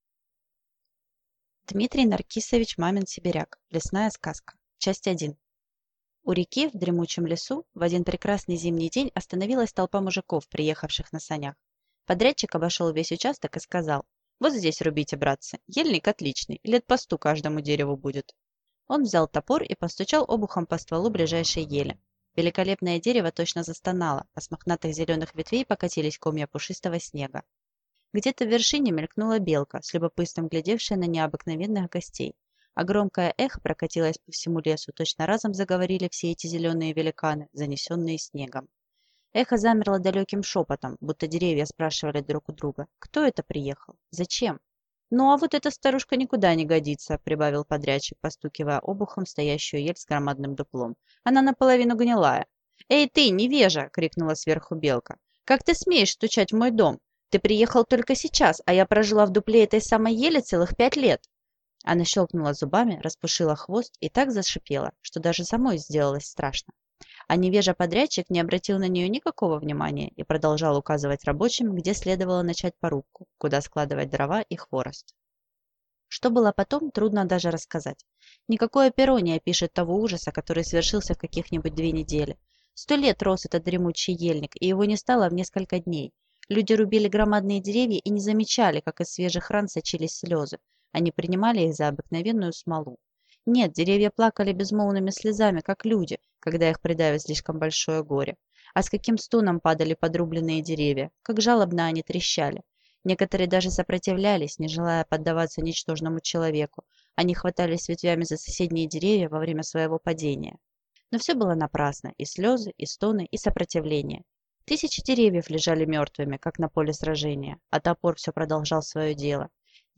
Аудиокнига Лесная сказка | Библиотека аудиокниг